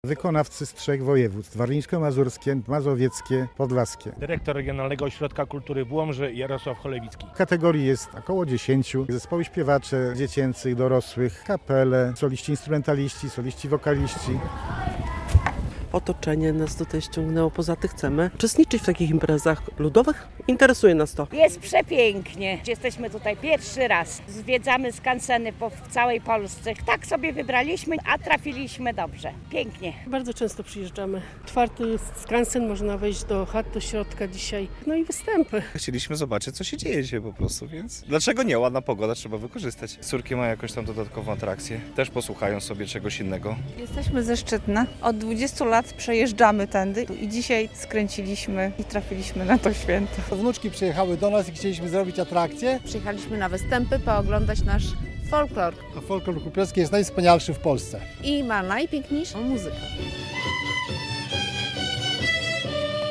Muzyką i śpiewem ludowym rozbrzmiewa w sobotę (14.06) amfiteatr nad Narwią w Nowogrodzie. W przeglądzie konkursowym XXXII Ogólnopolskich Dni Kultury Kurpiowskiej bierze udział około 200 wykonawców - solistów oraz członków zespołów ludowych i kapel.